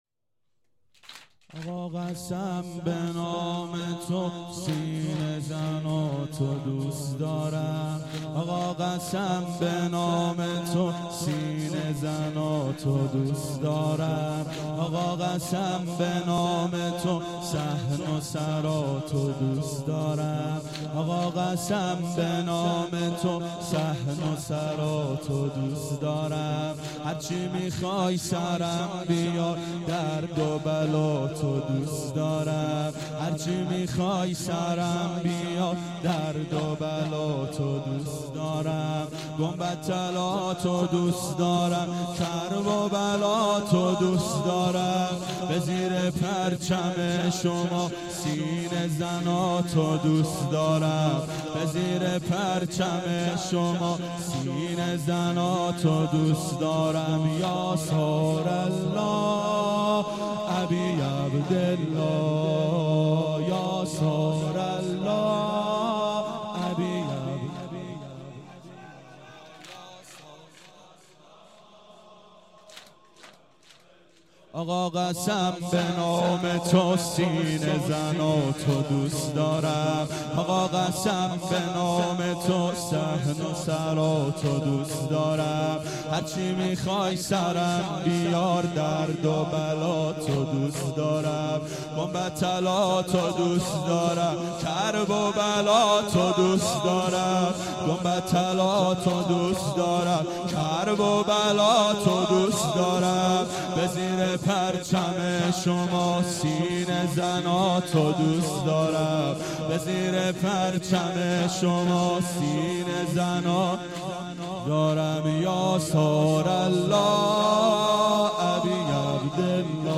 • دهه اول صفر سال 1391 هیئت شیفتگان حضرت رقیه سلام الله علیها (شام غریبان)